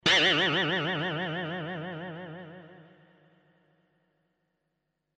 La molla   mp3 05'' 82 Kb
molla.mp3